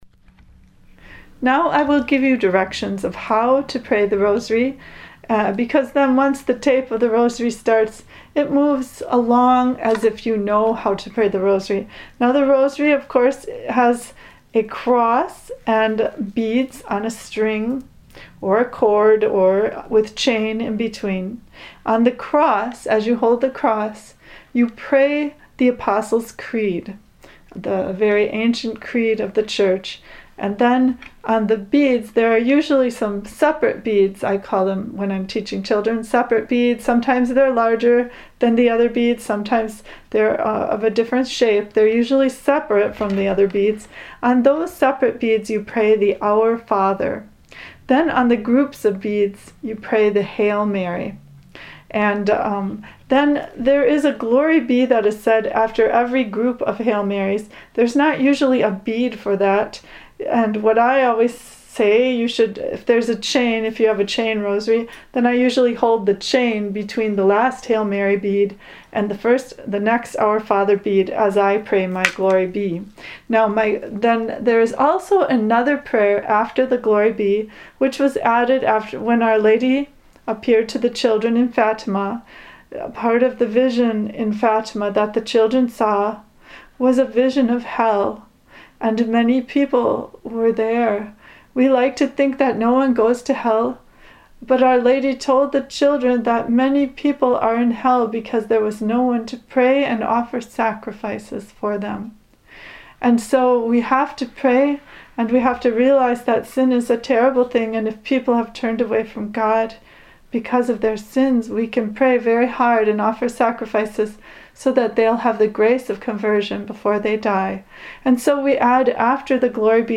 Below you will find some information and the recitation of the Rosary.